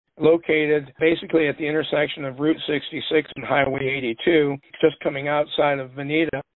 A new theme park being built in northeast Oklahoma will bring with it a real “Route 66” vibe. State Senator Mike Bergstrom says the American Heartland Theme Park will be constructed along the historic road in Vinita.